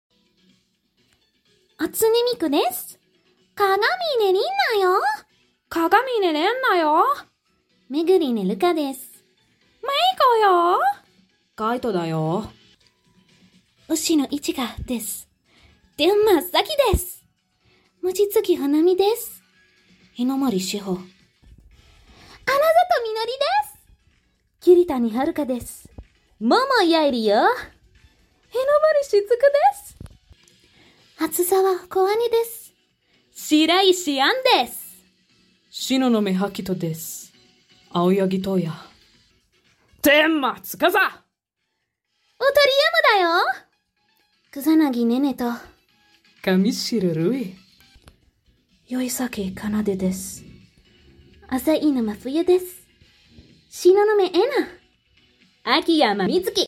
my voice impressions of pjsk sound effects free download
my voice impressions of pjsk characters!